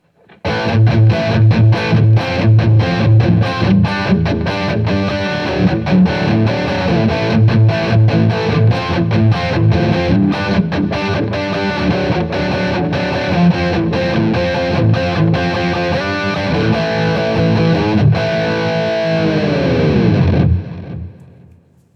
’80s Sounding Nonsense
As usual, for these recordings I used my normal Axe-FX Ultra setup through the QSC K12 speaker recorded into my trusty Olympus LS-10.
Well, towards the end I just wailed on the bridge pickup because the Thor’s Hammer setting just took me over.